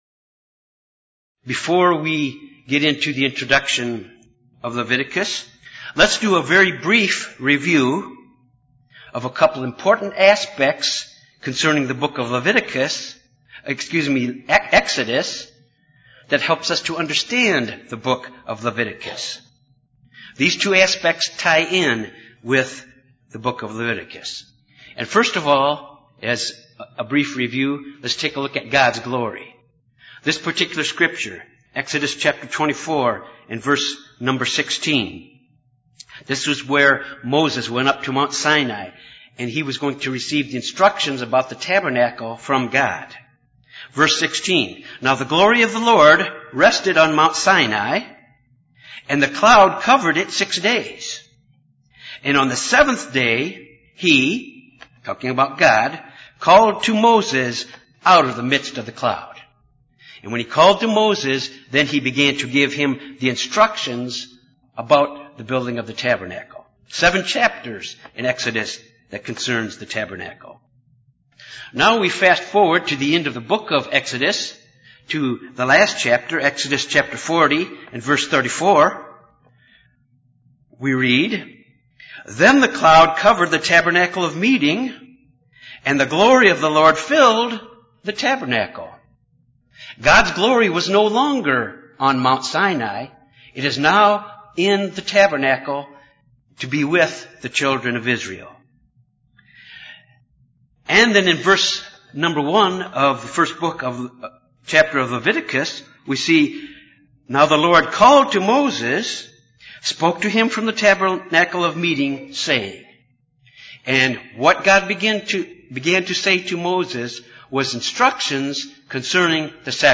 This Bible study deals with how the book of Leviticus focuses on the way God’s people should interact with His holiness, in their worship and other aspects of their life. However, the book deals more with the priests than the Levites and enlarges upon matters involving the ordering of worship at the divine sanctuary that are mentioned only briefly in Exodus.